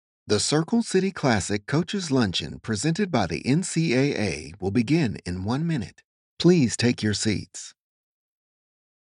Southern United States
Middle Aged
Senior